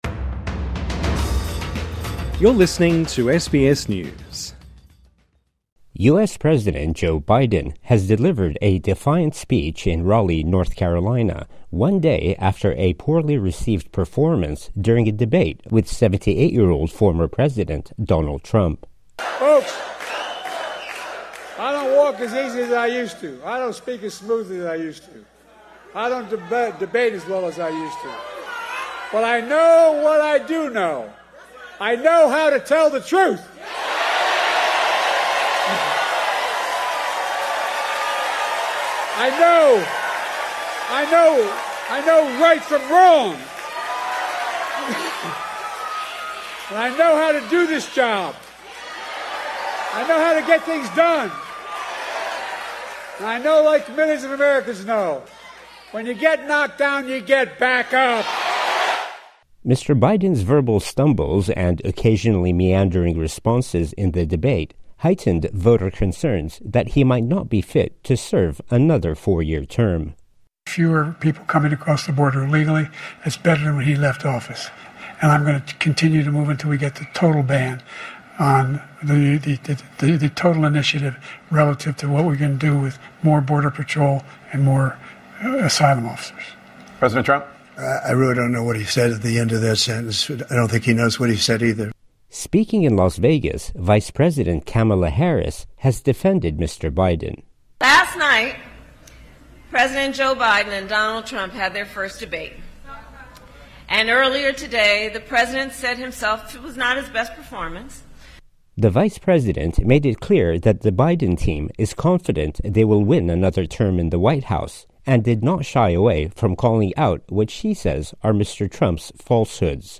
TRANSCRIPT U-S President Joe Biden has delivered a defiant speech in Raleigh, North Carolina one day after a poorly-received performance during a debate with 78-year-old former President Donald Trump.